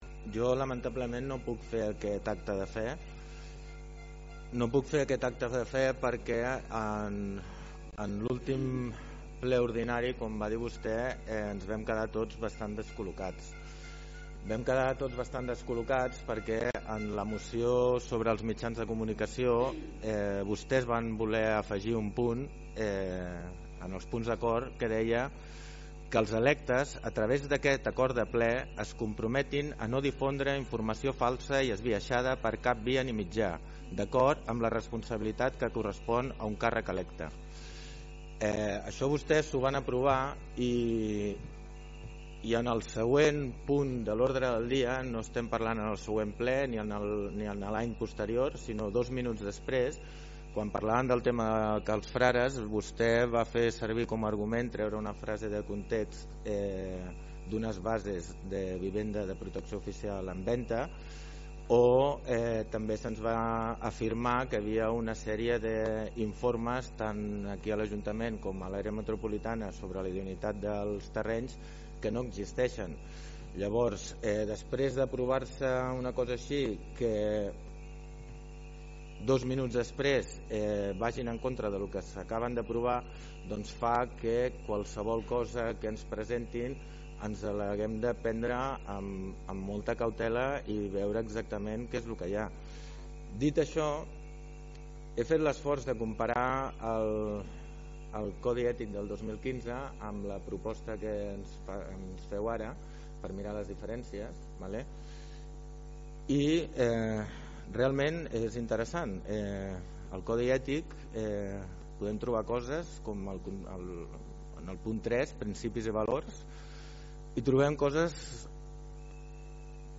Per altra banda, el portaveu d’ERC, Alex Van Boven, va votar en contra perquè, segons va dir, ell no podia fer aquest acte de fe.